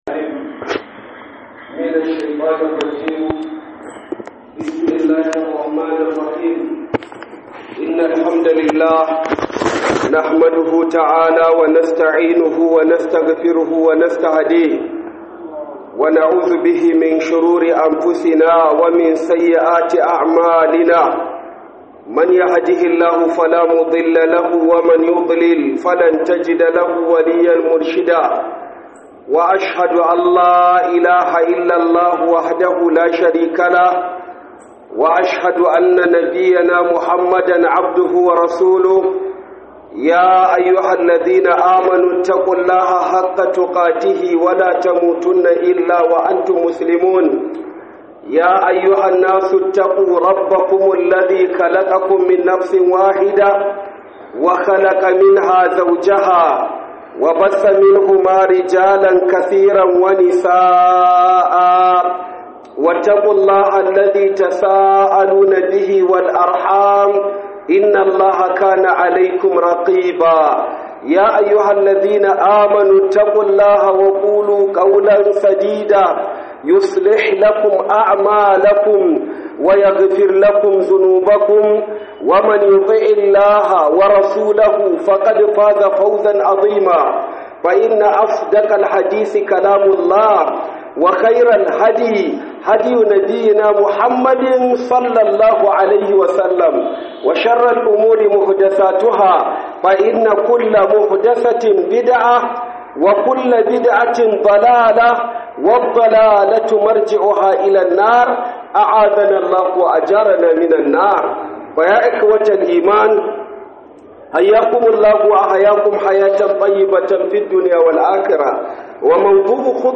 Khudubar NEPA Ningi - Cututtukan zuciya